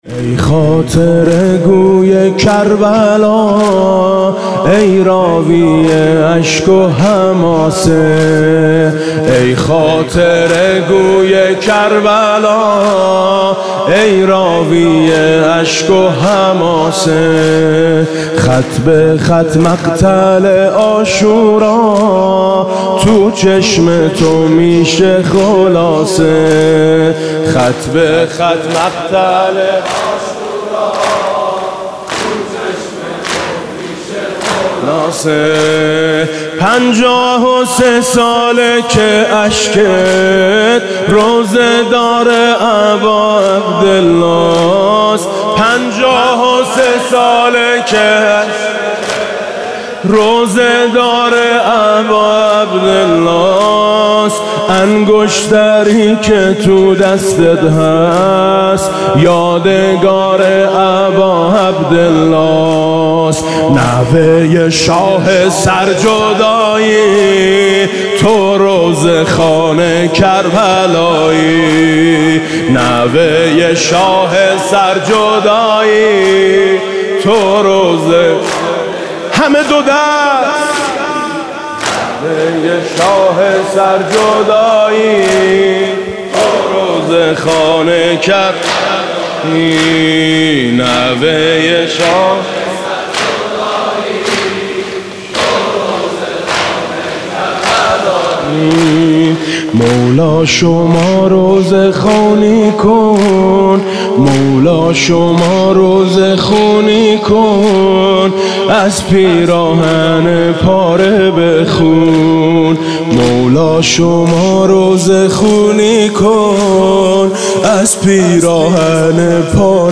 28 مرداد 97 - مسجد علی بن موسی الرضا - زمینه - ای خاطره گوی کربلا، ای راوی اشک و حماسه
شهادت امام باقر (ع)
زمینه حاج میثم مطیعی